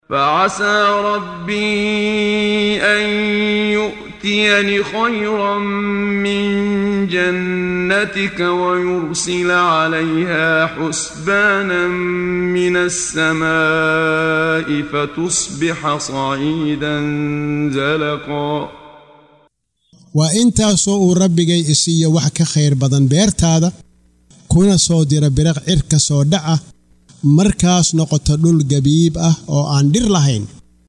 Waa Akhrin Codeed Af Soomaali ah ee Macaanida Suuradda Al-Kahaf ( Gebiga ) oo u kala Qaybsan Aayado ahaan ayna la Socoto Akhrinta Qaariga Sheekh Muxammad Siddiiq Al-Manshaawi.